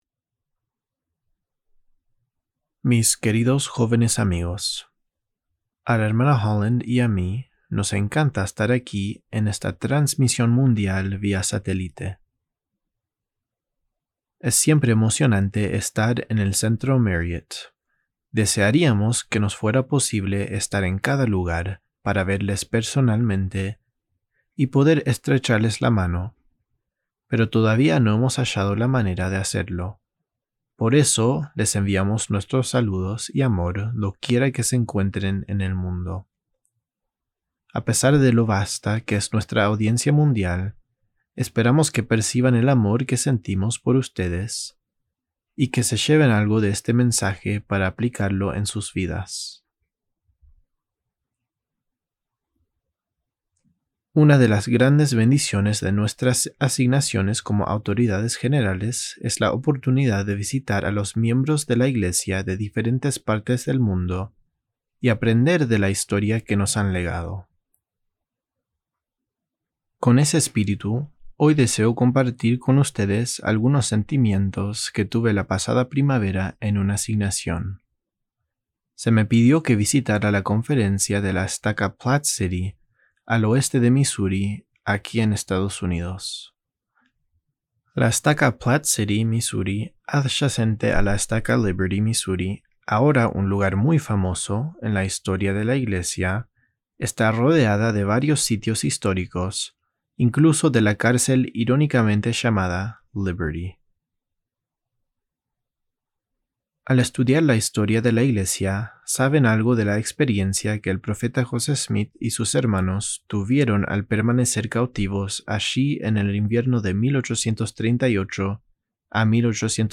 Audio recording of Las enseñanzas de la cárcel de Liberty by Jeffrey R. Holland
Devocional